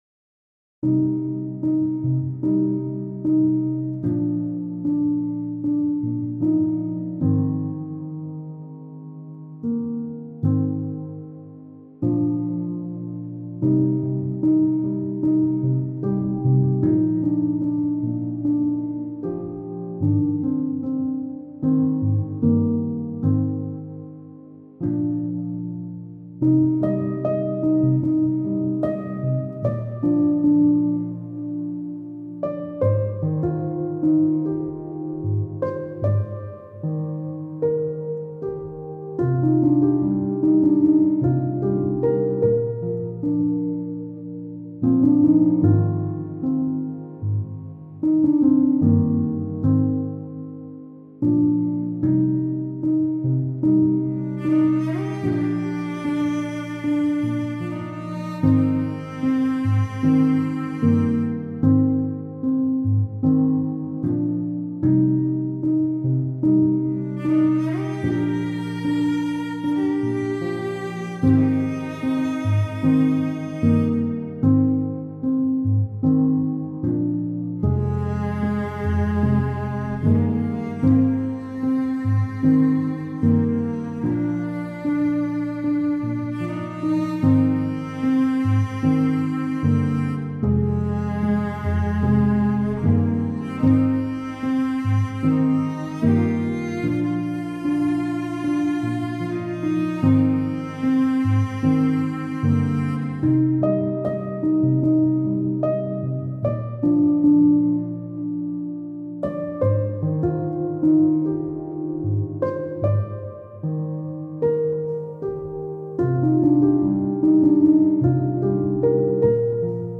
سبک موسیقی بی کلام